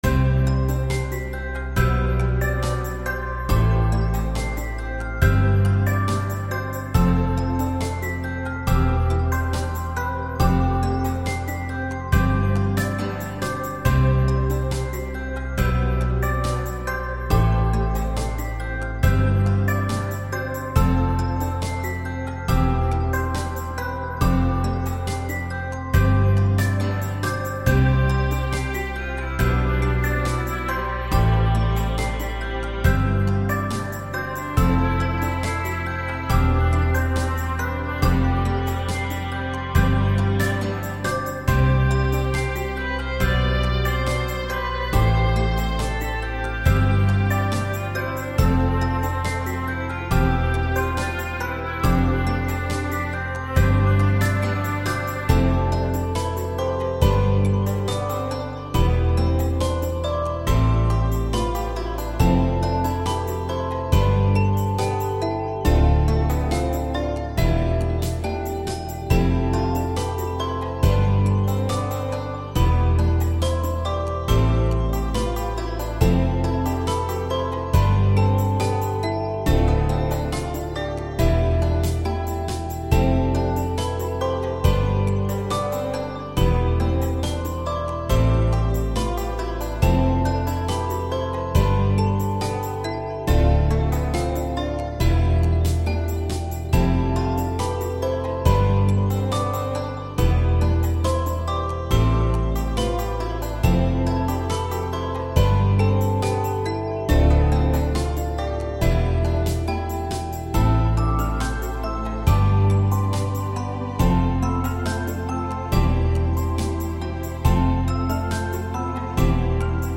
A relaxing tune meant to portray a calm and peaceful sanctuary.